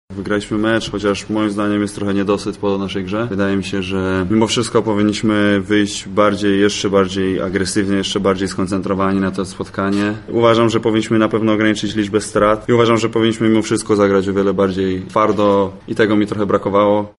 • mówił reprezentant Polski, Mateusz Ponitka.